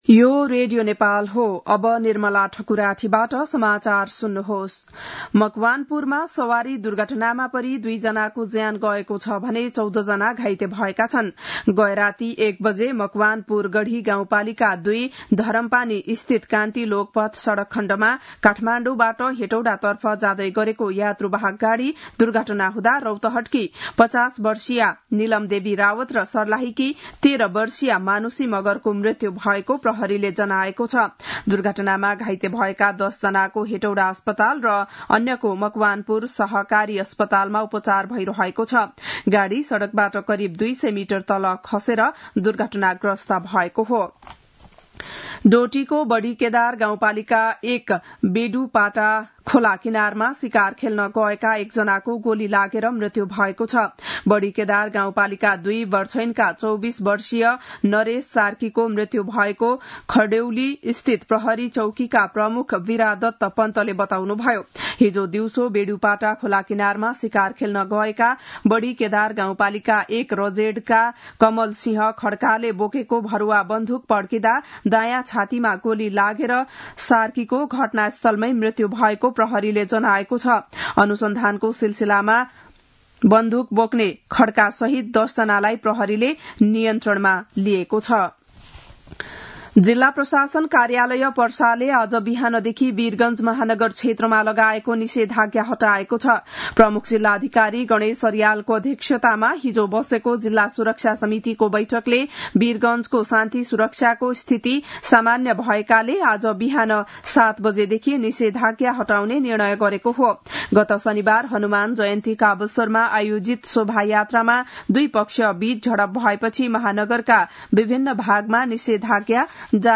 बिहान ११ बजेको नेपाली समाचार : ५ वैशाख , २०८२
11-am-news-1-8.mp3